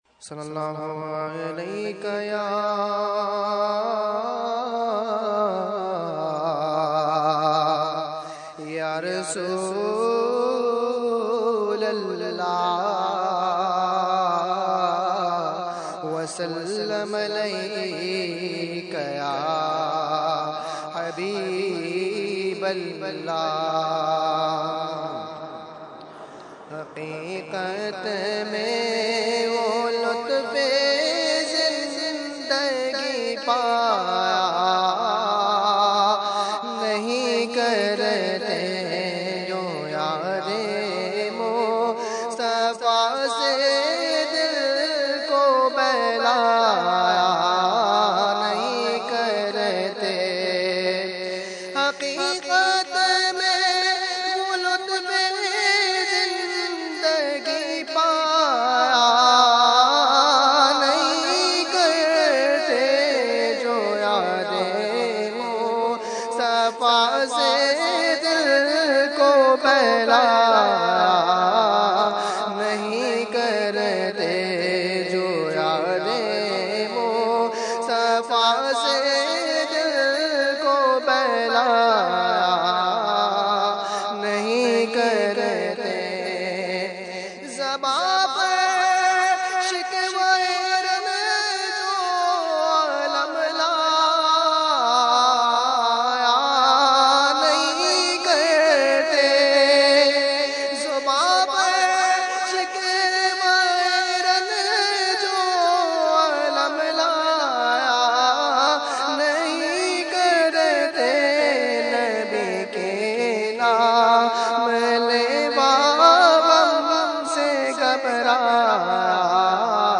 Category : Naat | Language : UrduEvent : Urs Qutbe Rabbani 2014